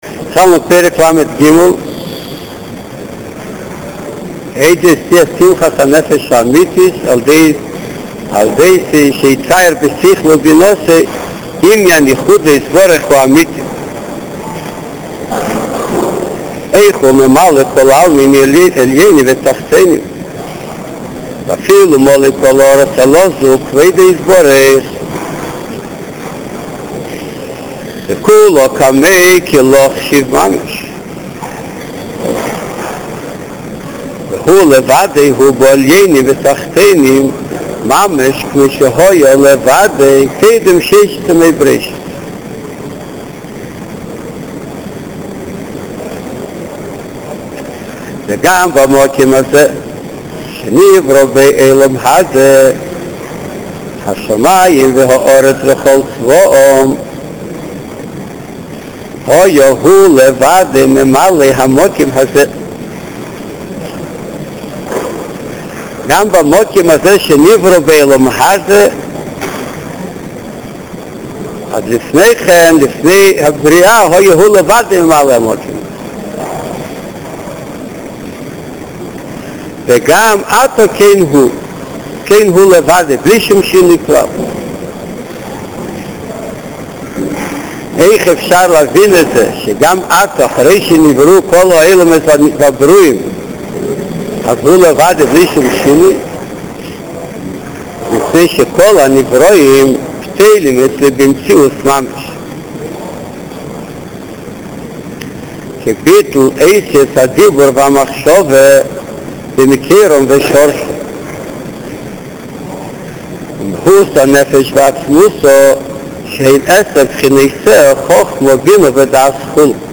שיעור תניא